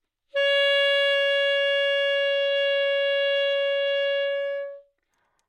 萨克斯中音单音（吹得不好） " 萨克斯中音 F5 baddynamics
描述：在巴塞罗那Universitat Pompeu Fabra音乐技术集团的goodsounds.org项目的背景下录制。单音乐器声音的Goodsound数据集。
标签： 好声音 单注 多样本 萨克斯 纽曼-U87 F5 中音
声道立体声